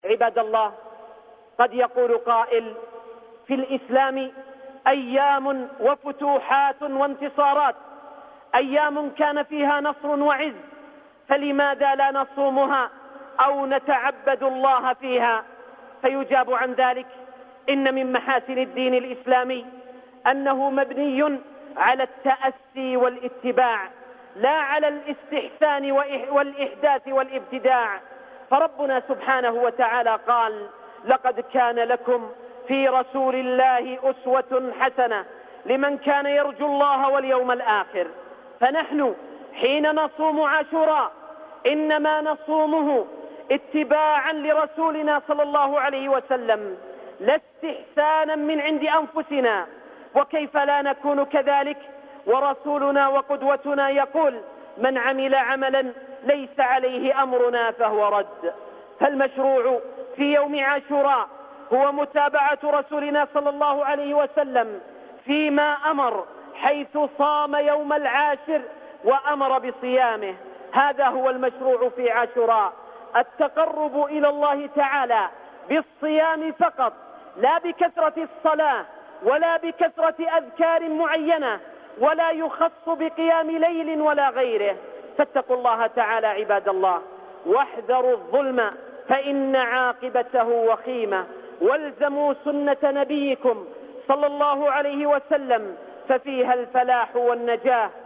مقطع من خطبة الجمعة العاشر من محرم يوم عز وتمكين.